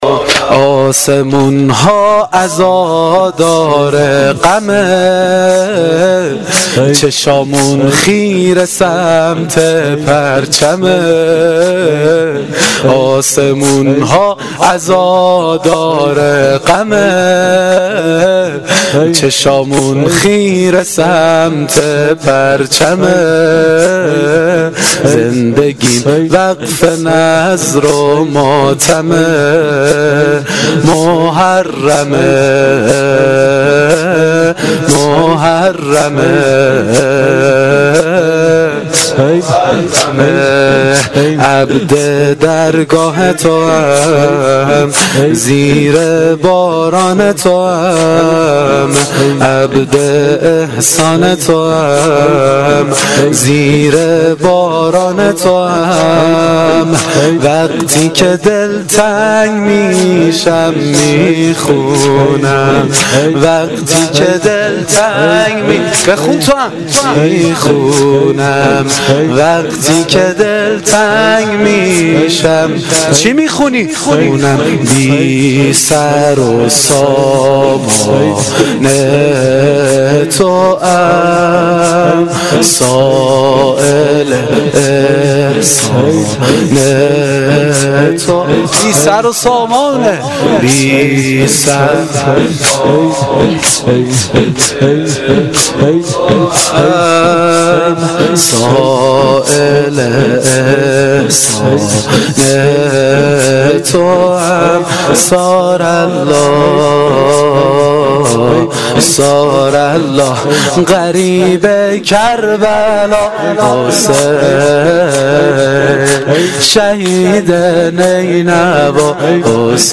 زمینه شب دهم محرم الحرام 1396 (شب عاشورا)
هیئت جواد الائمه
روضه